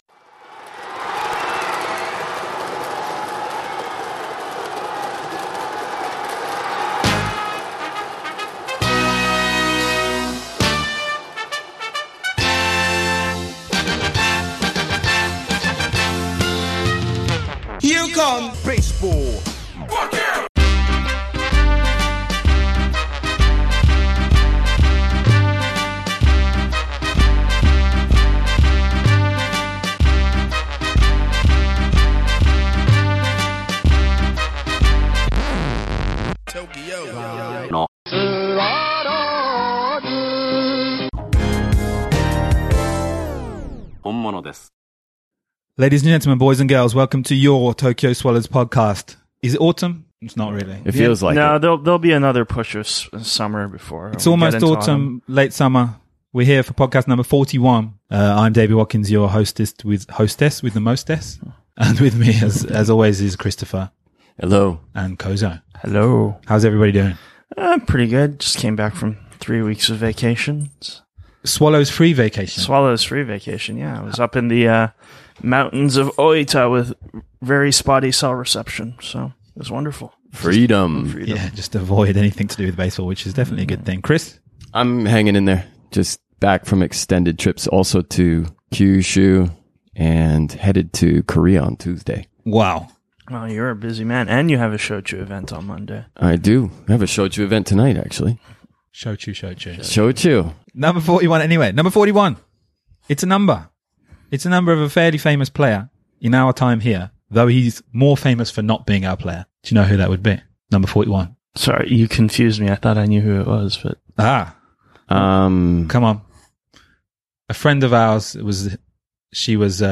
Recorded prior to the game on Sunday September 3, 2017.